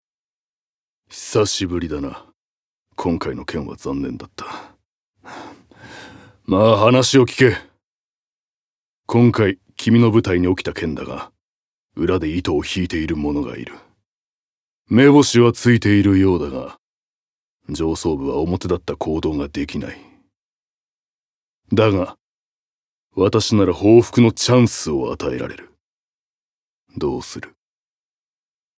ボイスサンプル
セリフ５